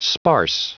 Prononciation du mot sparse en anglais (fichier audio)
Prononciation du mot : sparse